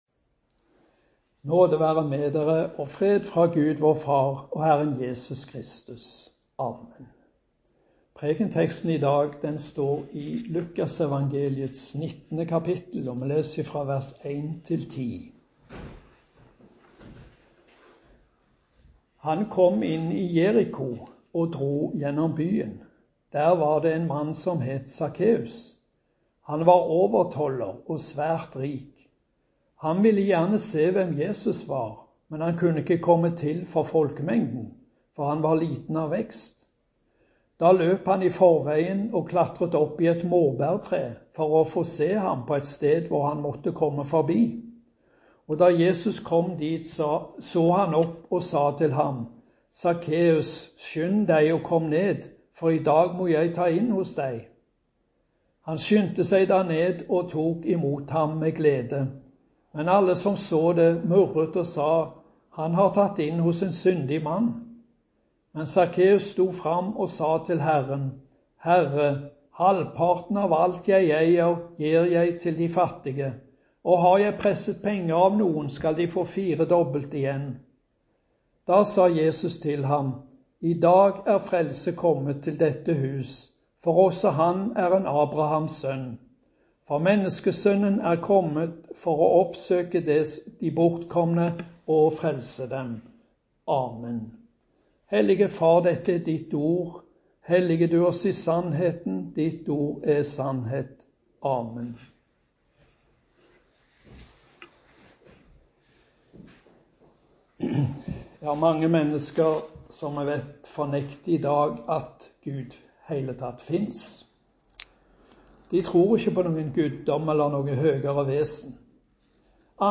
Preken på 2. søndag etter Kristi åpenbaringsdag